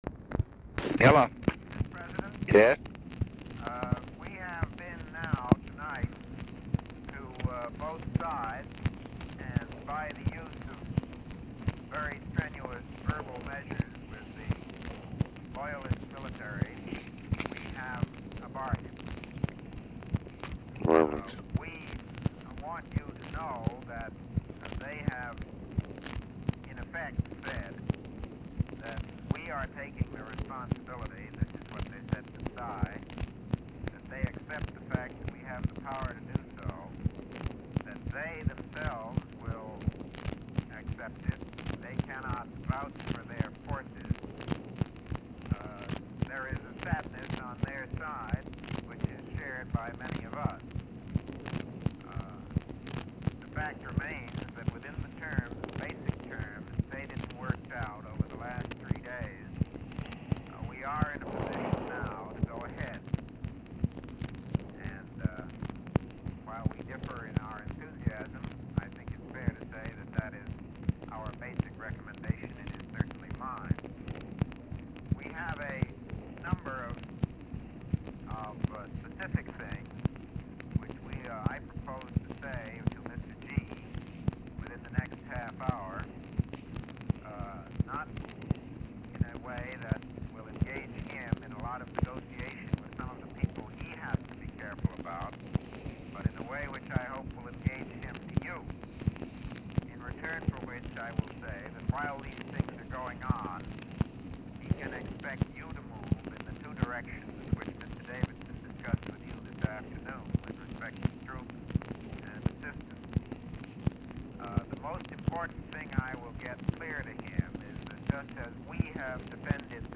Telephone conversation # 7735, sound recording, LBJ and MCGEORGE BUNDY, 5/18/1965, 12:01AM
Format Dictation belt
Location Of Speaker 1 Mansion, White House, Washington, DC